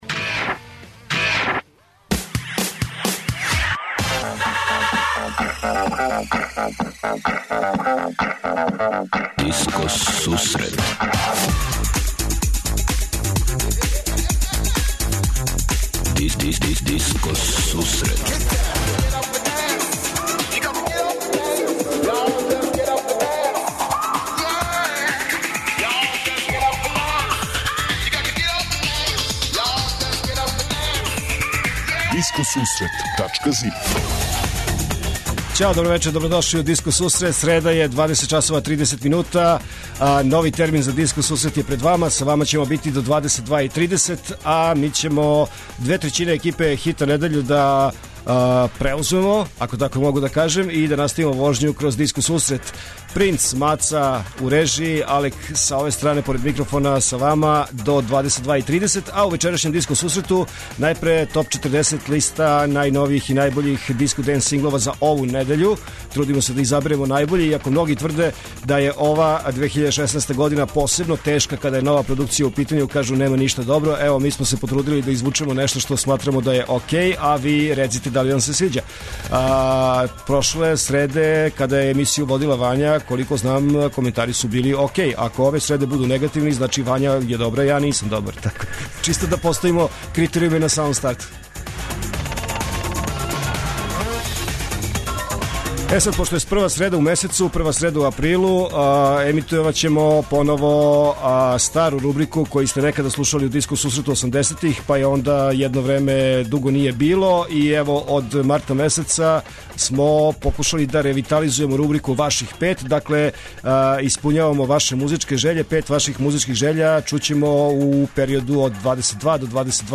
Топ листа 40 највећих светских диско хитова.